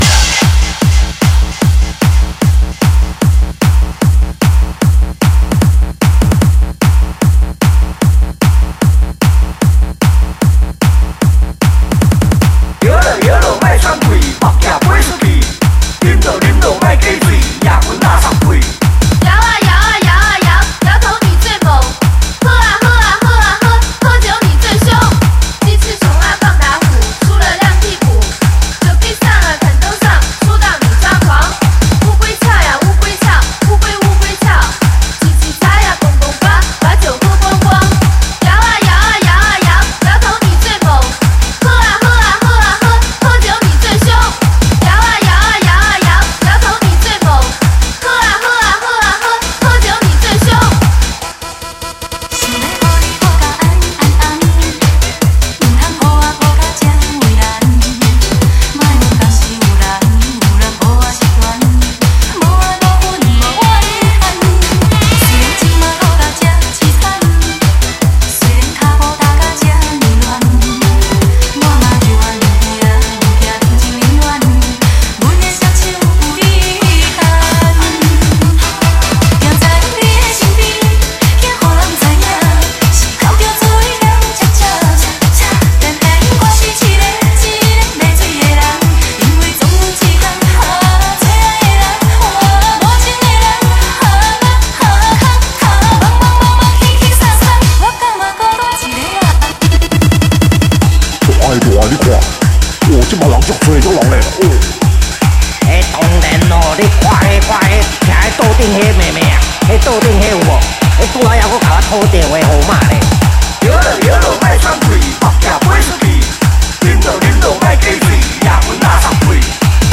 现场演出版 闽南语DJ主打至尊舞曲